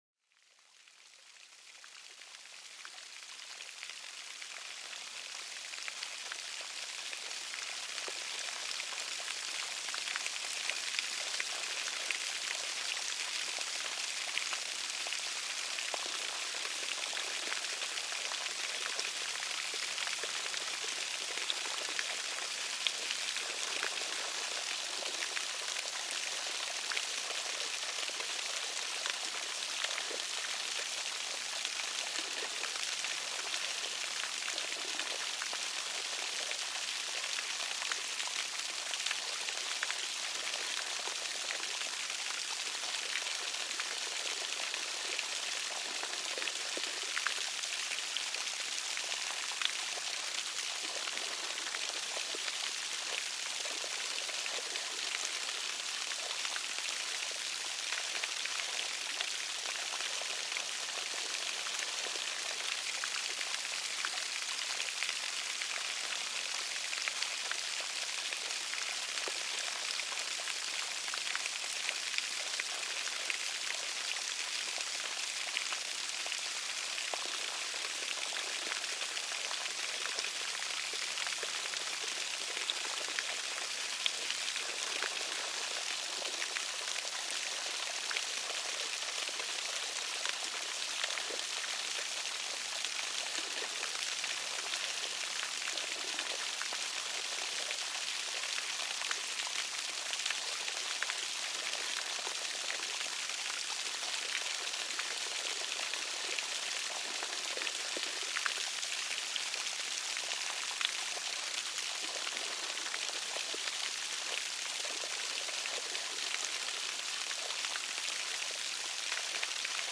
Cascada Suave: Relajación al Ritmo del Agua